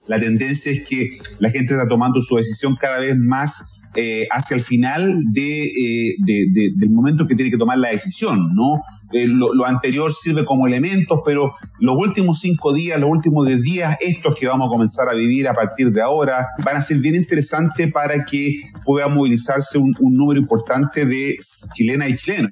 En tanto, en conversación con Expresso Bío-Bío